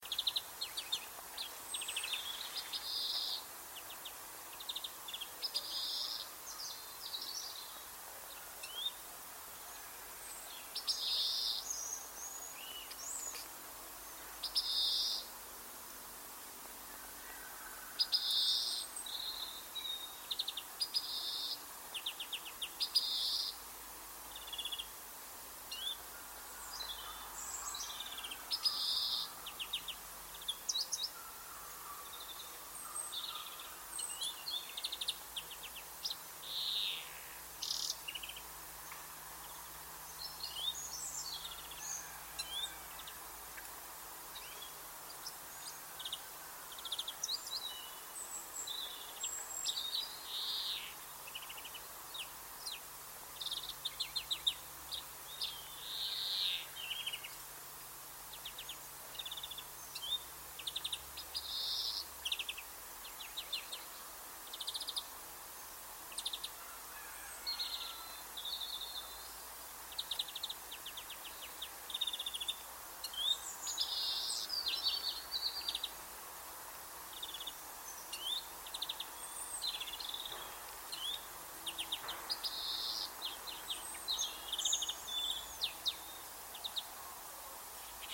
Hra k MZD Ptaků – Chloris chloris